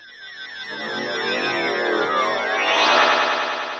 shard_float.ogg